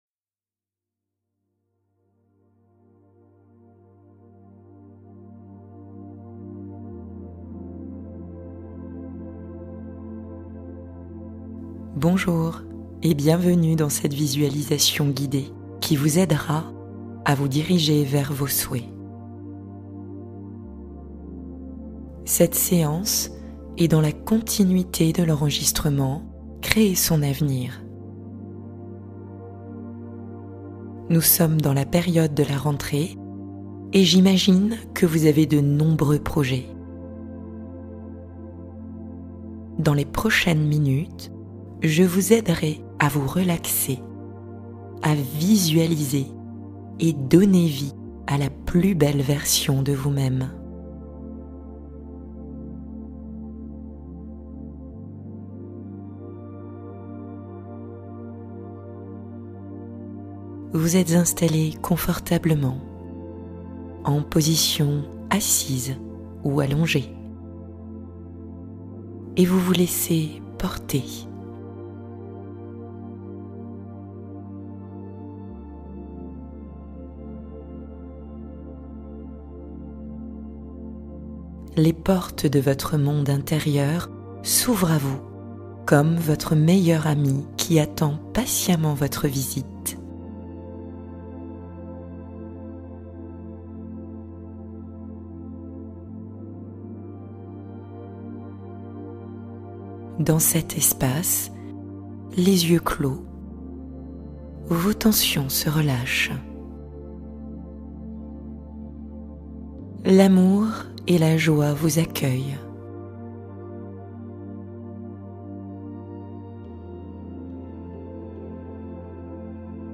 Créer son avenir #2 : visualisation guidée et transformation